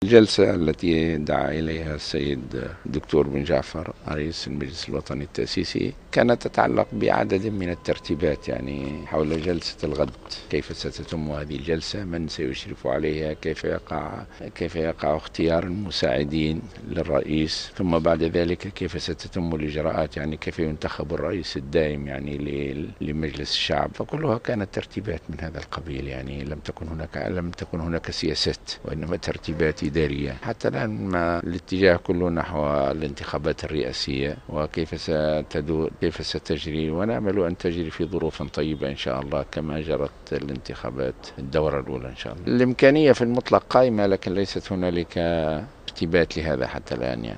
قال رئيس حركة النهضة راشد الغنوشي عشية اليوم الاثنين على هامش اجتماع ترتيبي للجلسة الإفتتاحية لأعمال مجلس نواب الشعب الجديد التي ستنعقد غدا الثلاثاء إن إمكانية ترأس نائب من كتلة النهضة لمجلس الشعب مازالت قائمة.